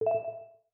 Message Ping 2.wav